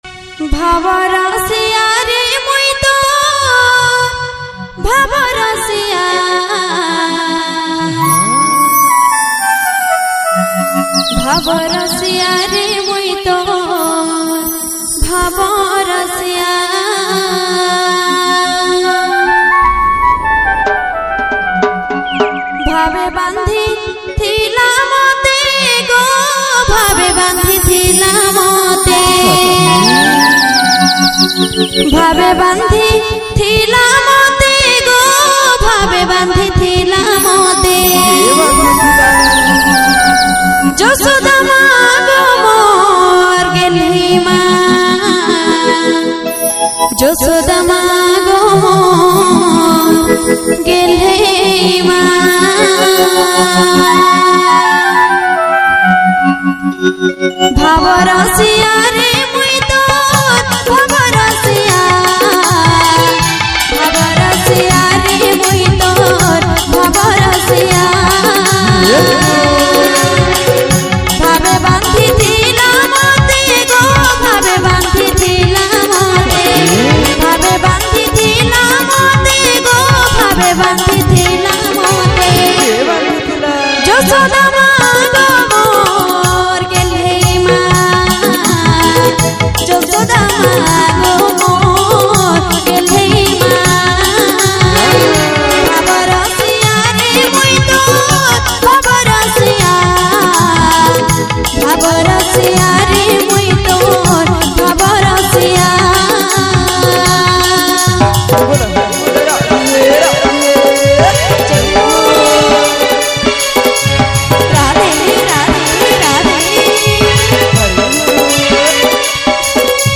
Odia Kirtan Bhajan Songs